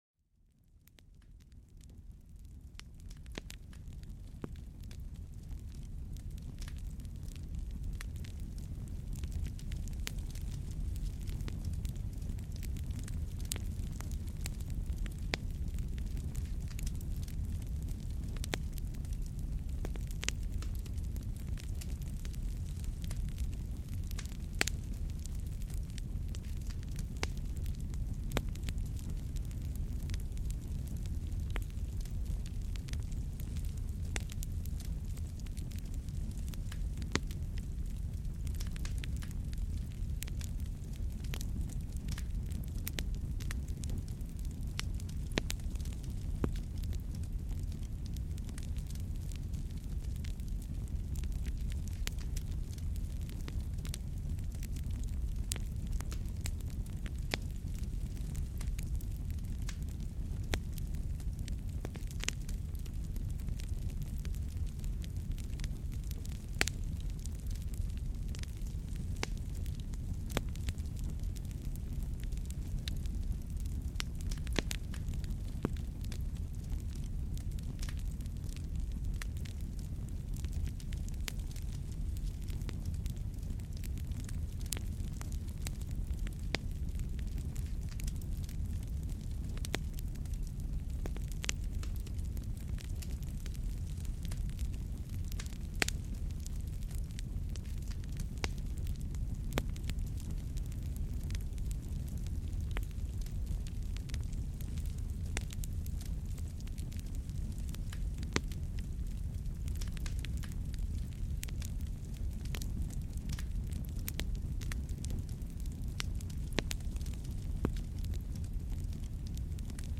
Le son apaisant du feu pour se relaxer et s'endormir paisiblement